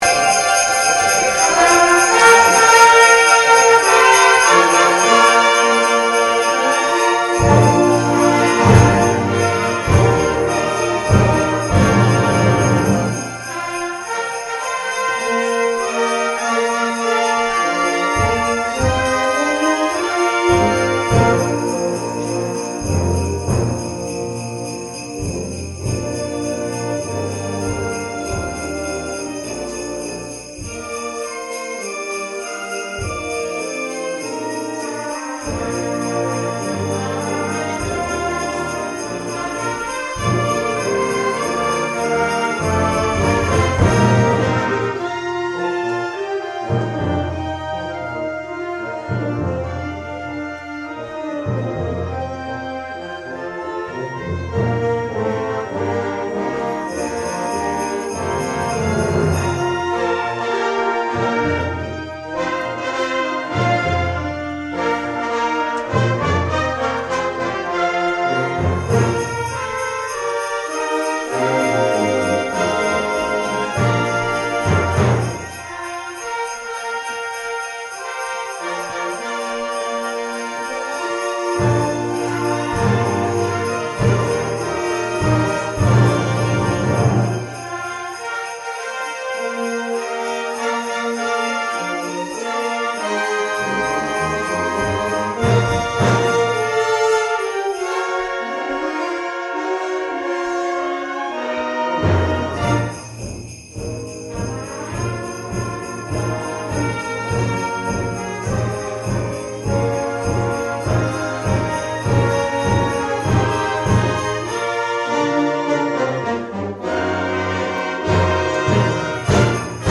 Wave Farm | The Coxsaxckie Athens Community Band and Athens' Reformed Church Choir performed a holiday concert at the church.
The Coxsaxckie Athens Community Band and Athens' Reformed Church Choir performed a holiday concert at the church.